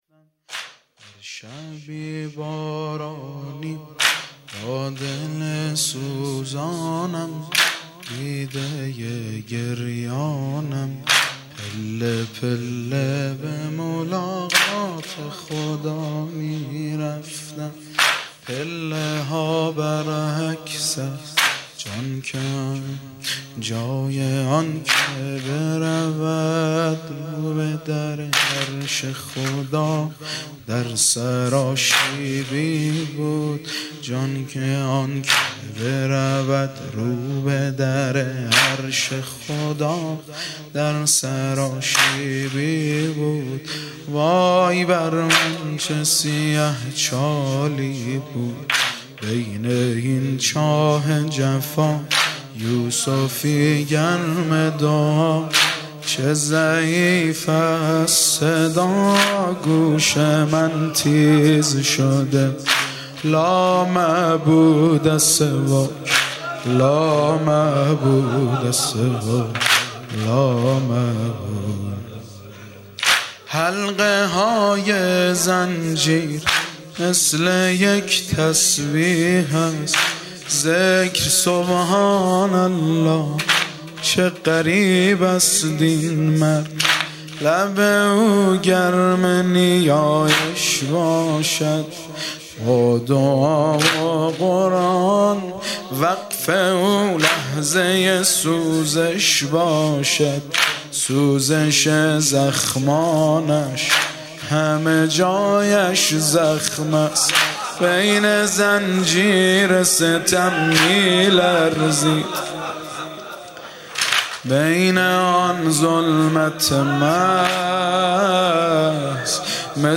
با مداحی
روضه
شور